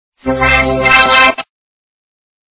Главная » Рингтоны » Рингтоны приколы